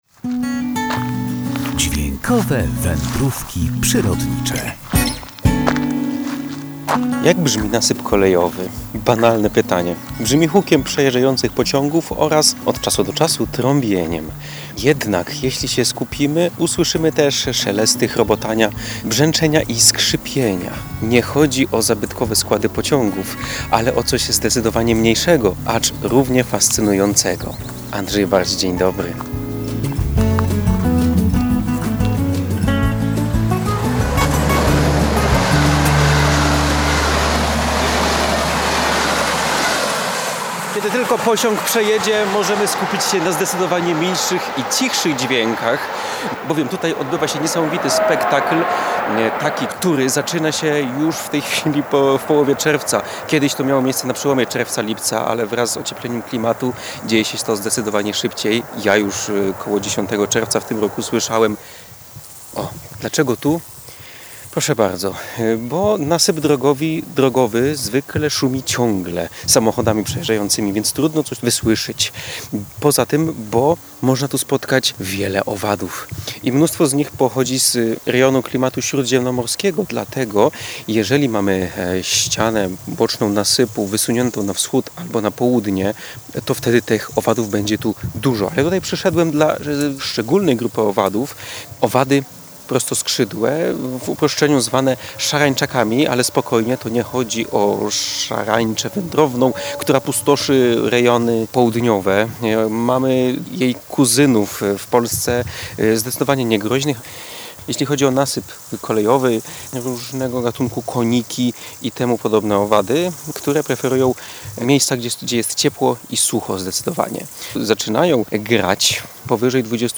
Brzmi hukiem przejeżdżających pociągów oraz – od czasu do czasu – trąbieniem. Jednak, jeśli się skupimy, usłyszymy też szelesty, brzęczenia, chrobotania i skrzypienia.